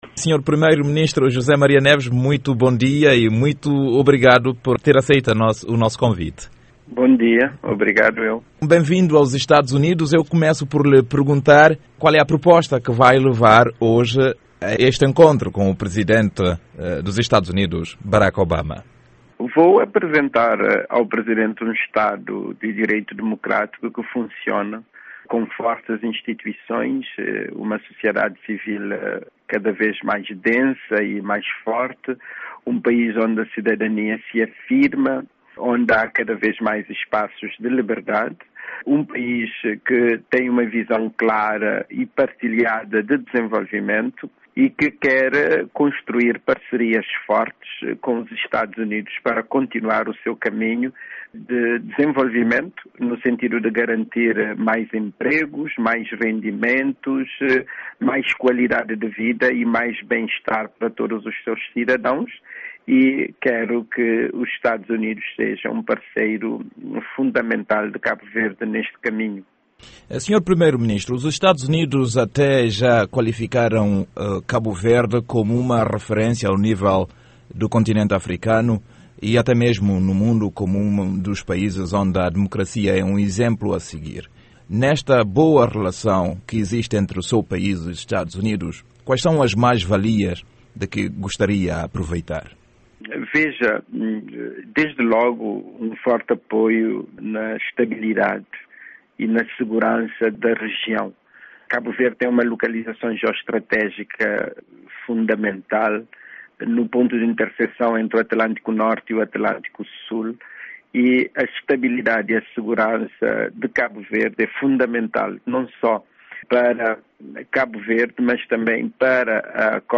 Entrevista de José Maria Neves a Voz da América